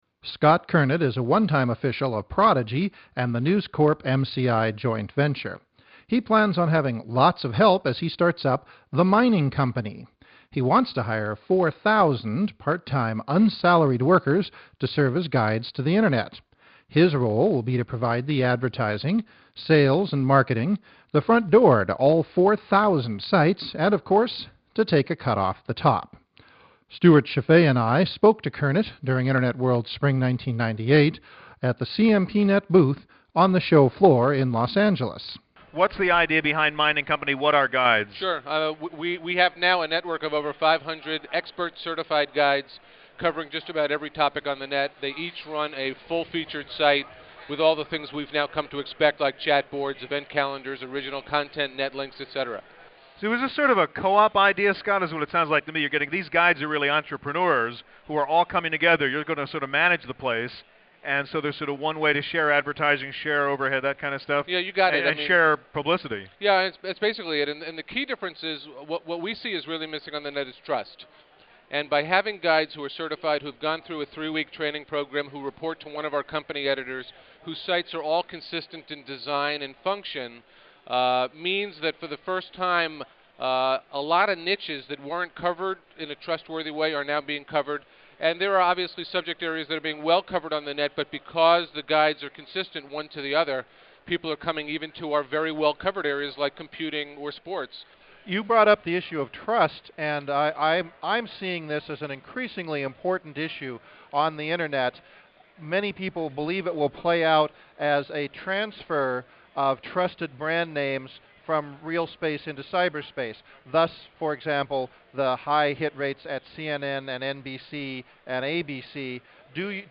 Interviewed
Co-opetition Online WHY WON'T YAHOO list the hundreds of guide sites that make up the Mining Company�s network? In an interview at Fall Internet World '97